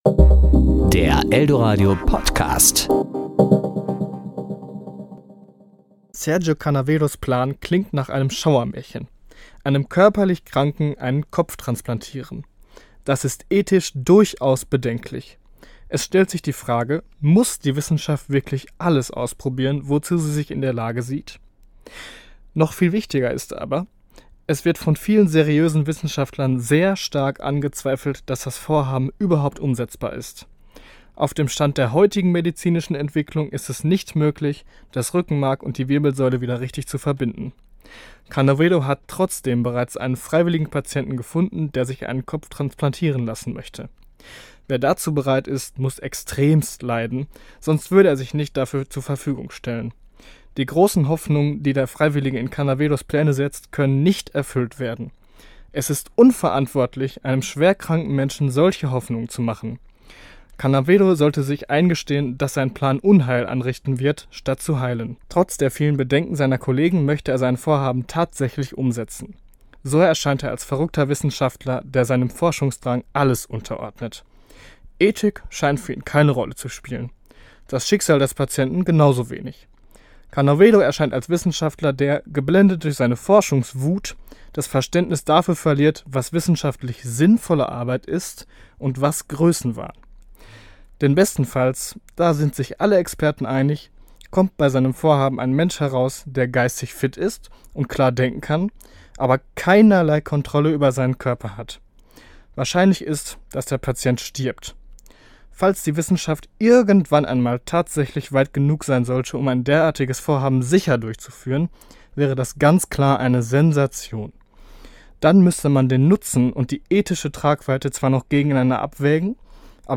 Beiträge  Ressort: Wort  Sendung